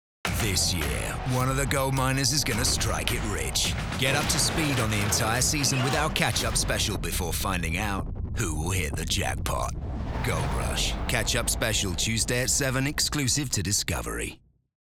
RP ('Received Pronunciation')
Bright, Upbeat, Cool